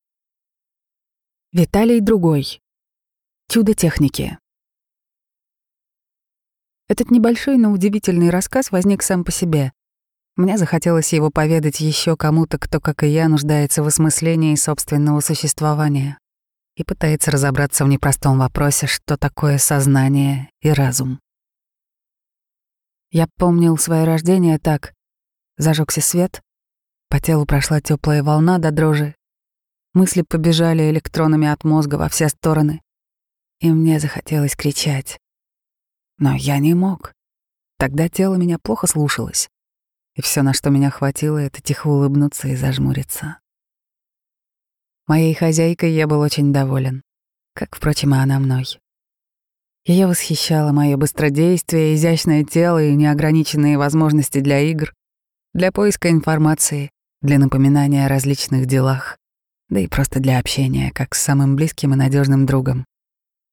Аудиокнига Чудо техники | Библиотека аудиокниг